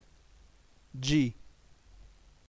◊ 1 (settima lettera dell’alfabeto italiano) g / G f, sa gi: